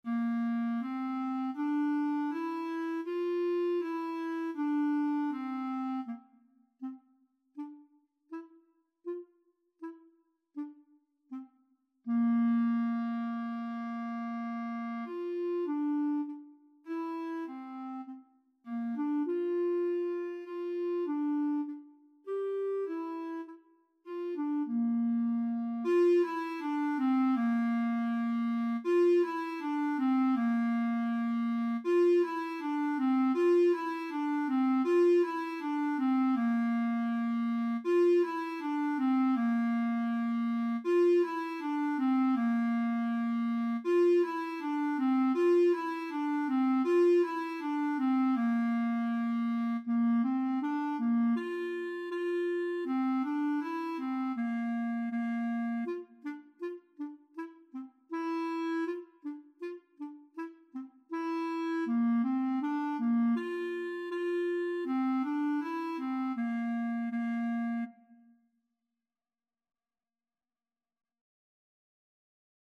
Bb4-G5
4/4 (View more 4/4 Music)
Beginners Level: Recommended for Beginners
Clarinet  (View more Beginners Clarinet Music)
Classical (View more Classical Clarinet Music)